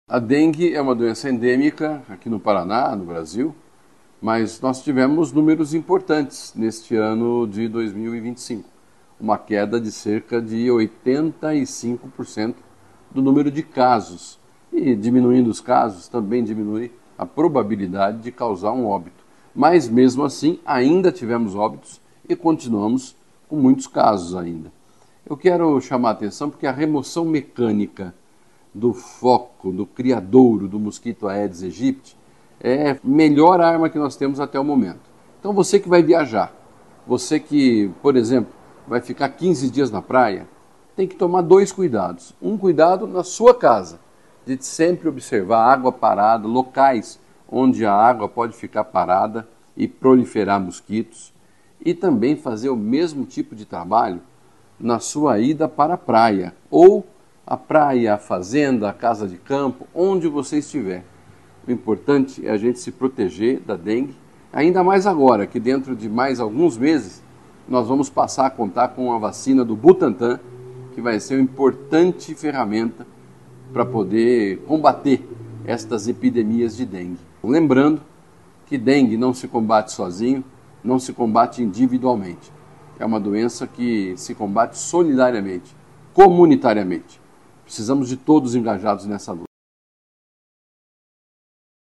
Sonora do secretário da Saúde, Beto Preto, sobre os cuidados com a dengue